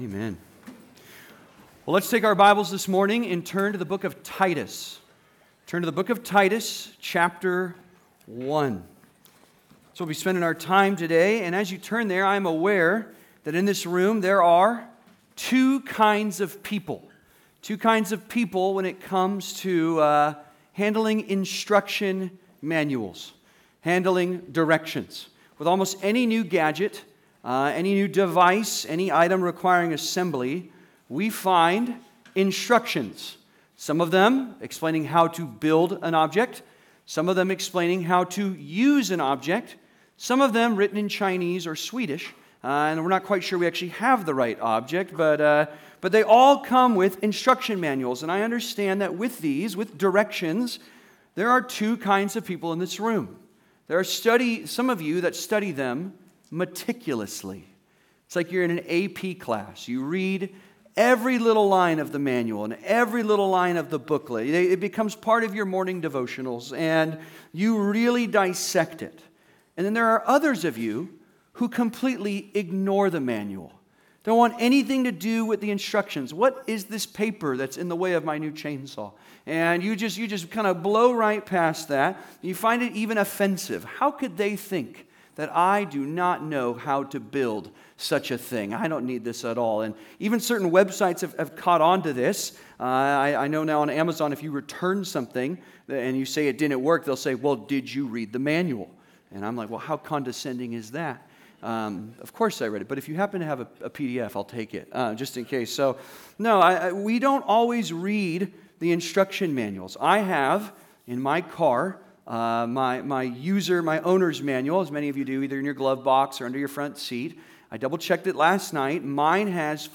(Sermon) - Compass Bible Church Long Beach